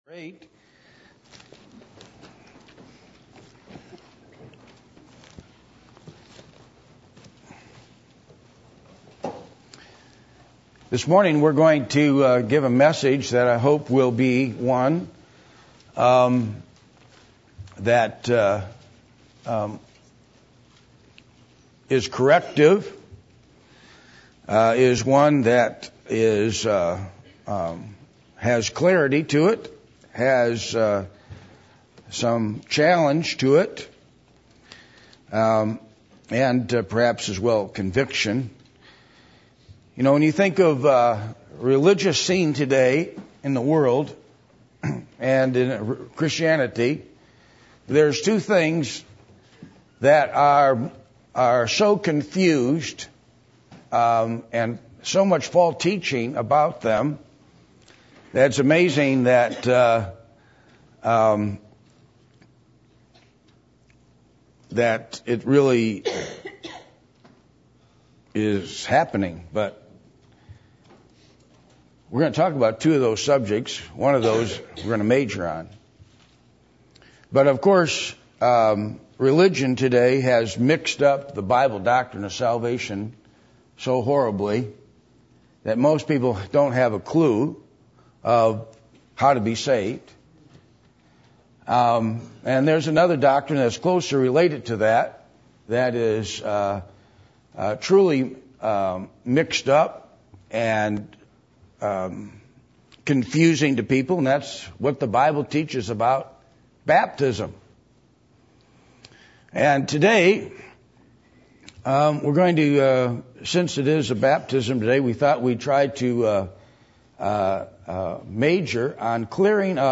Acts 8:26-38 Service Type: Sunday Morning %todo_render% « What Kind of Spirit do you Have?